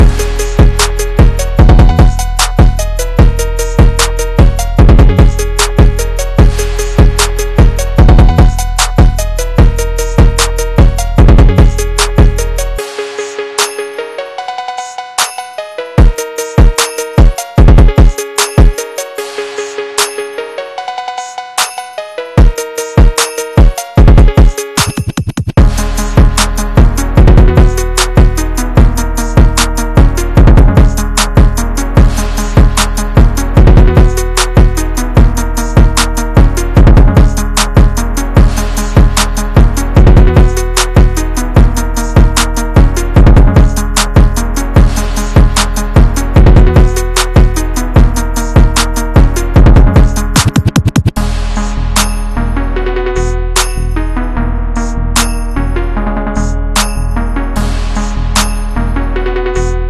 One of one Porsche 992 sound effects free download